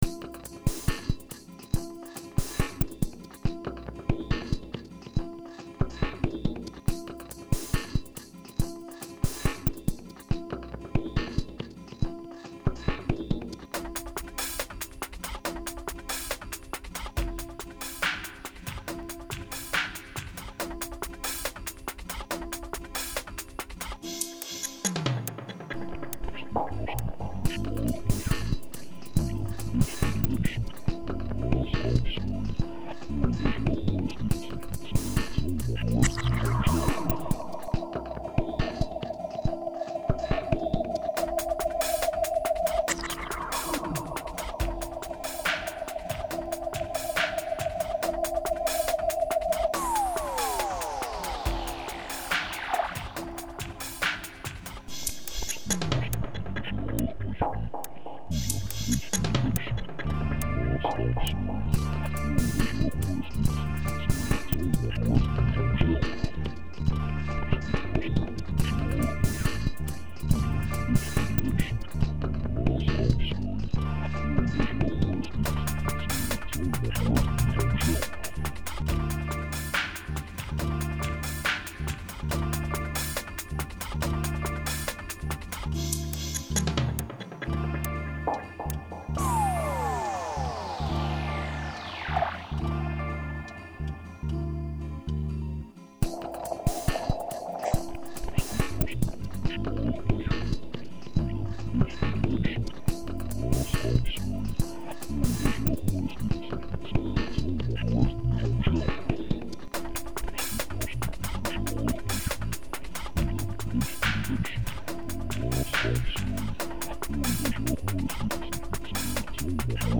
Dub Hypnotique et minimal pour les amateurs de plongée